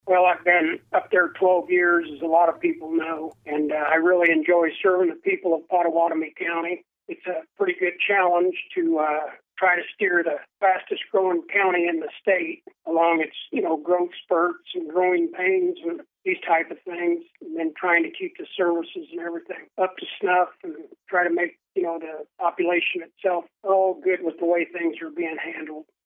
KMAN spoke with Weixelman about his motivation for running for reelection and his stances on various county issues.